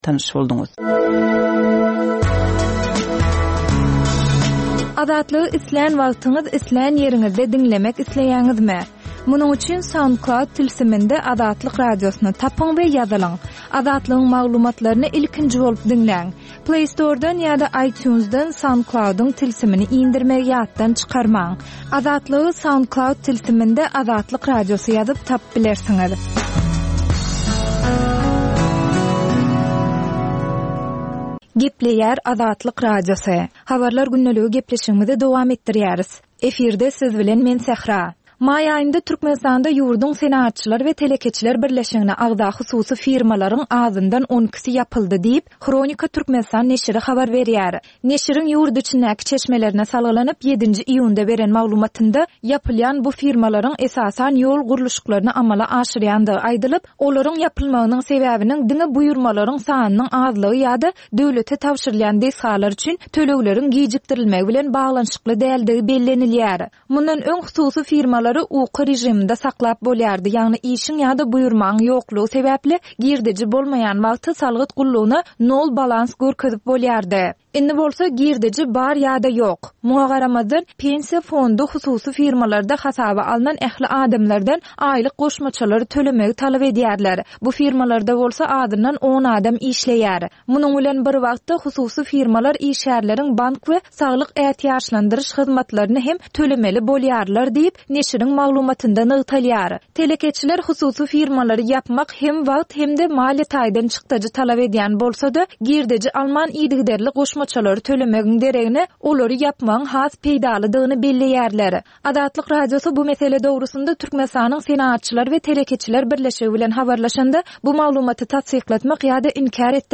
Türkmenistandaky we halkara arenasyndaky soňky möhüm wakalar we meseleler barada ýörite informasion-habarlar programma. Bu programmada soňky möhüm wakalar we meseleler barada giňişleýin maglumatlar berilýär.